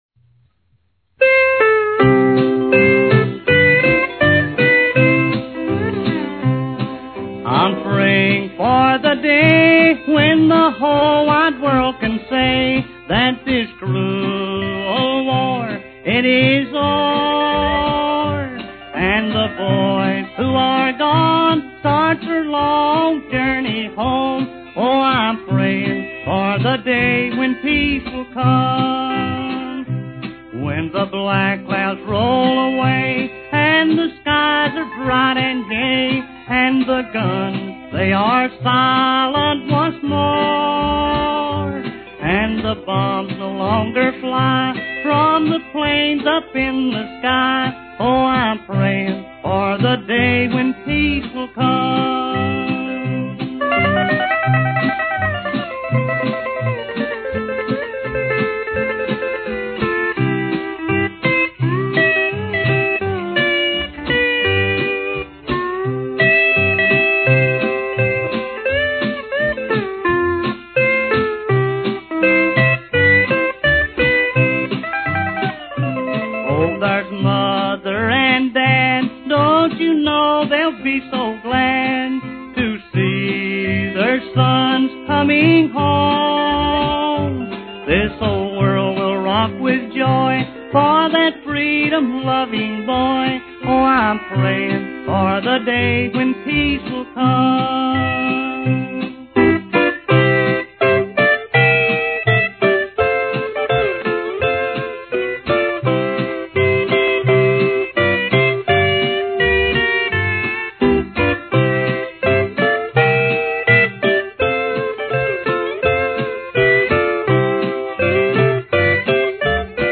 A Tribute To Old Time Country Music